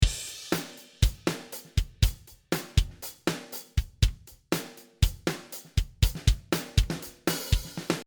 使用している音源は、Superior Drummer 2の標準キットをそのままパラアウトで使っています。
ロックなどに向く、アタック重視の音色
ドラム全体
高域を強調した事で、バチバチッという音になりました。